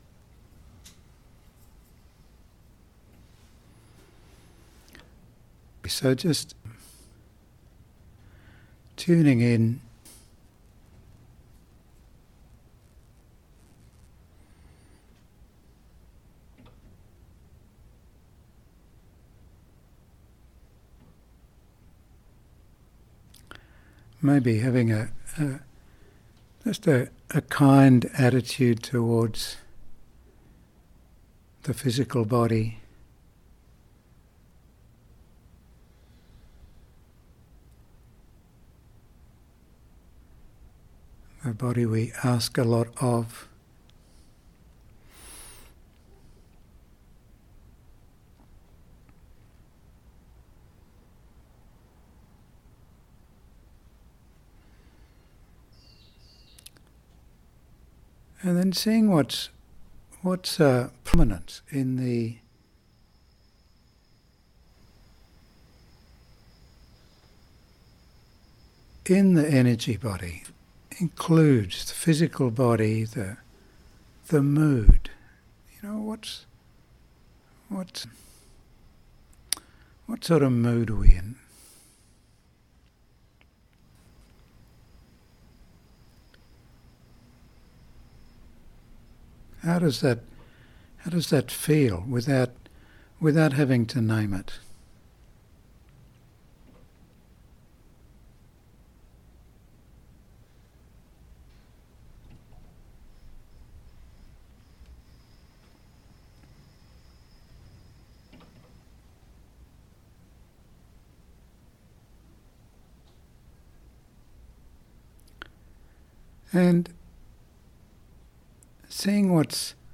Audio recordings Talks on Dharma Seed 2025 Energy Body Practice Guided Meditation 21 mins 05 secs. Canberra, October 2025